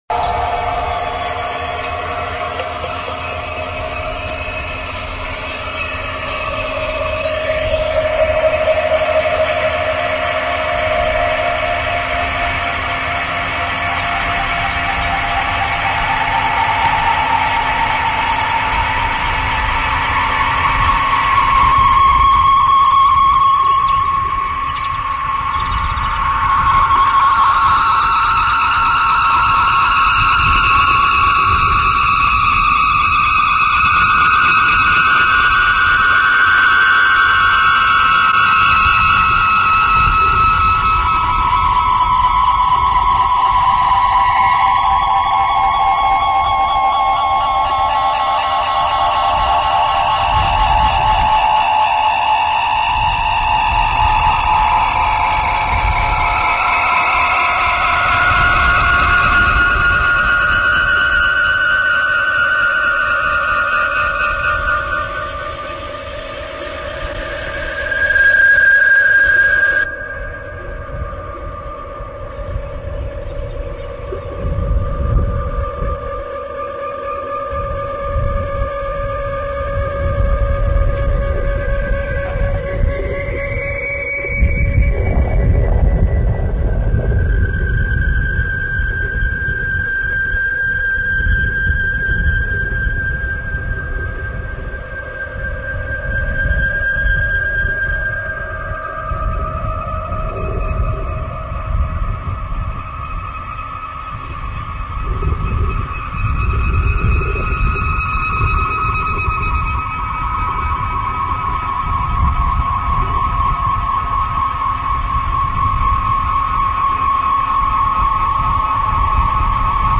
loud noise made during a tape-recording session, or a related UFO sound,
sirren.mp3